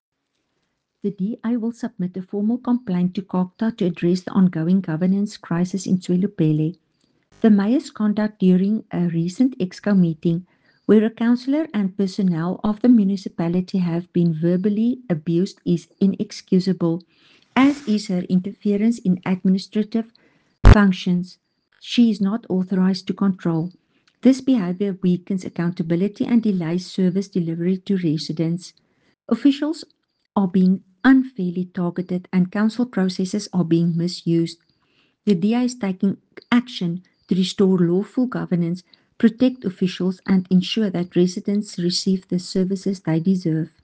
Afrikaans soundbites by Cllr Estelle Pretorius and Sesotho soundbite by David Masoeu MPL.